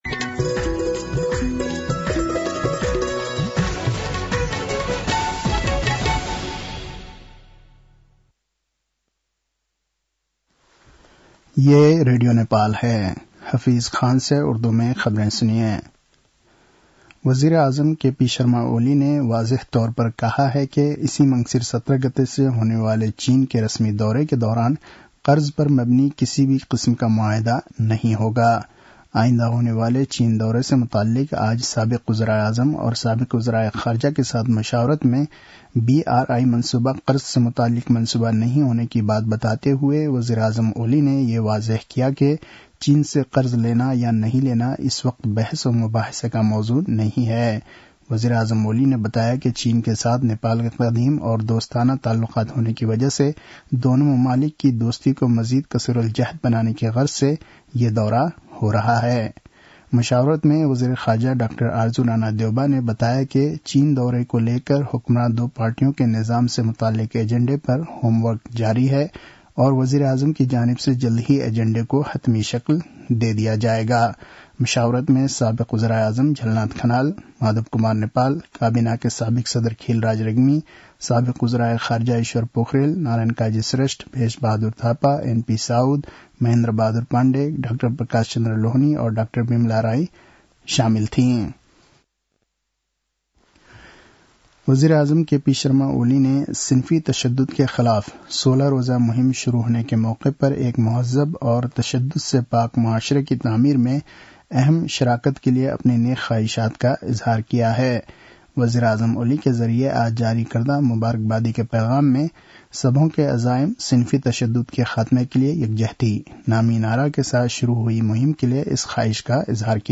उर्दु भाषामा समाचार : ११ मंसिर , २०८१